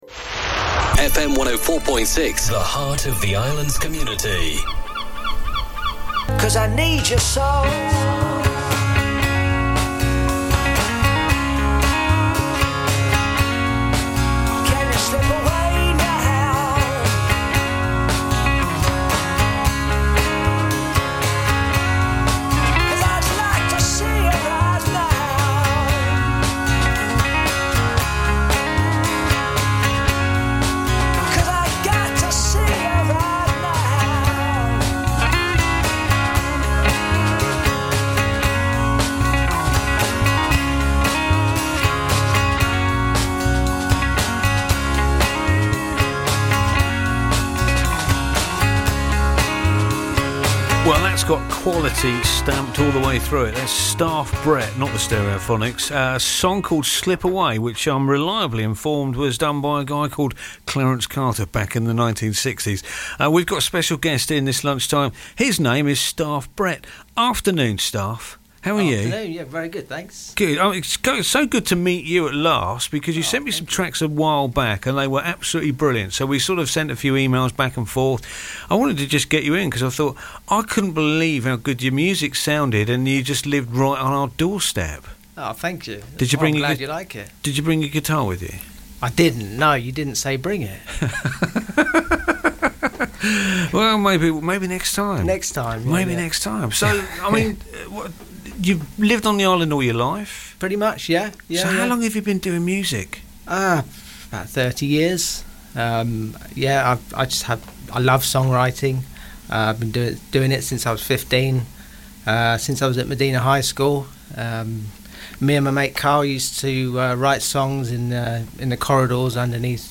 Vectis Interviews 2026